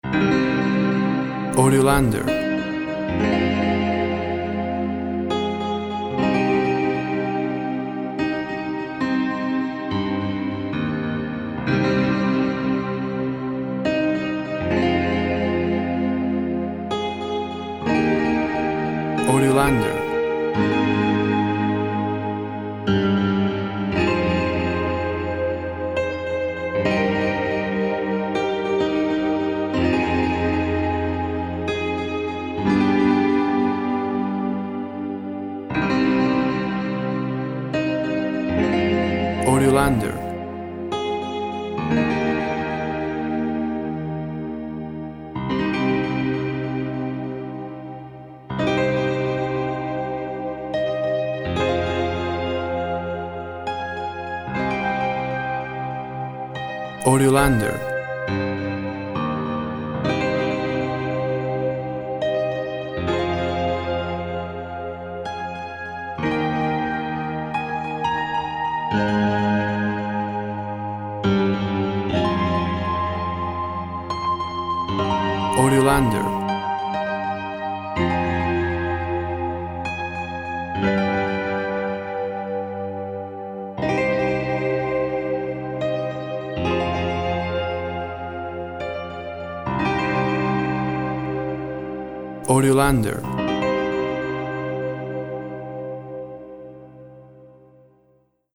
Piano playing through the vastness of space.
Tempo (BPM) 60